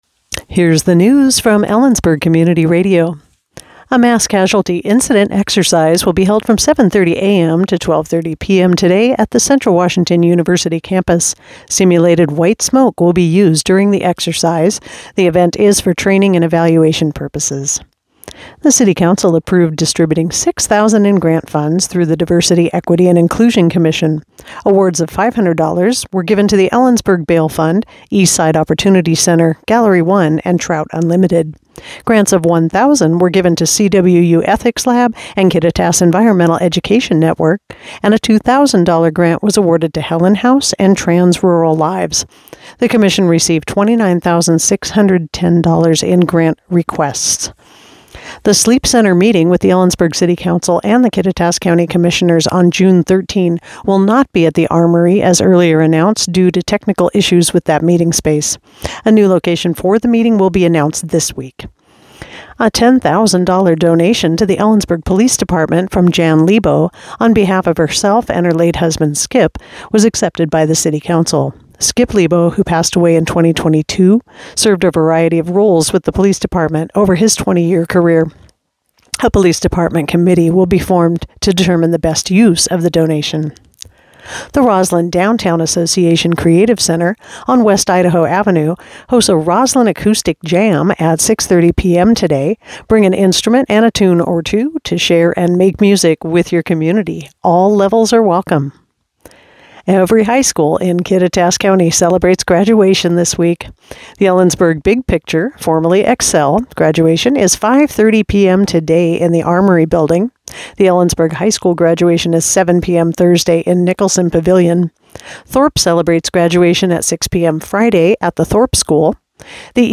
Click here to listen to today's newscast